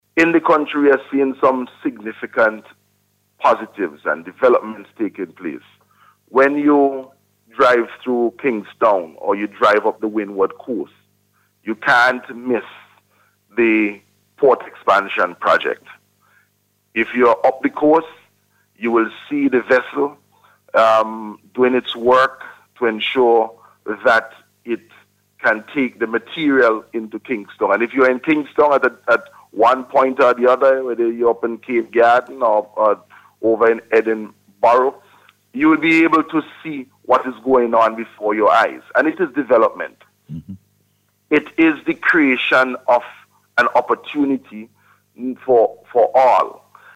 The Port Modernization Project was one of the projects highlighted by Minister Caesar on NBC Radio this morning.